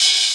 Open Hat
Original creative-commons licensed sounds for DJ's and music producers, recorded with high quality studio microphones.
soft-open-hi-hat-sound-g-sharp-key-03-qWd.wav